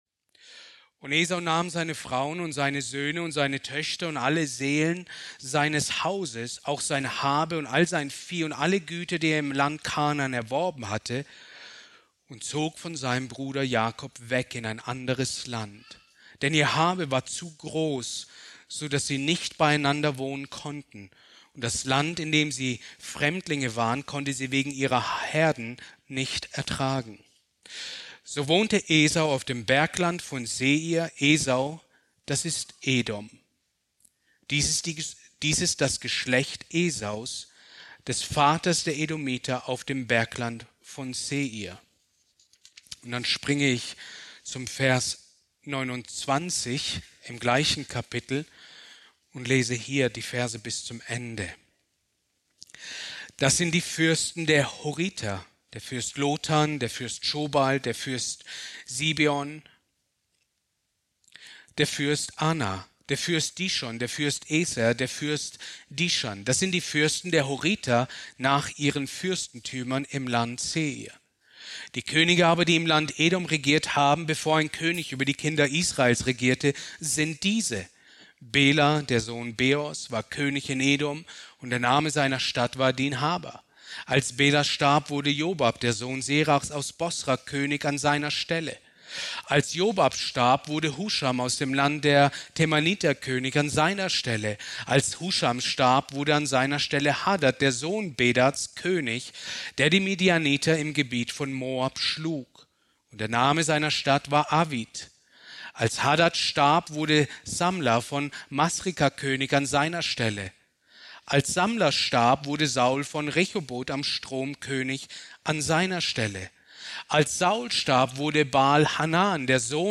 Predigt aus der Serie: "Genesis"